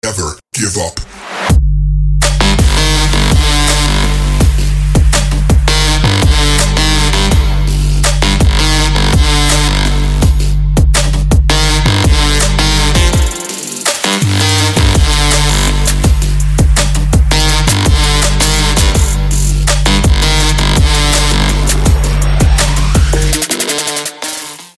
• Качество: 320, Stereo
Electronic
club
Bass
Hybrid Trap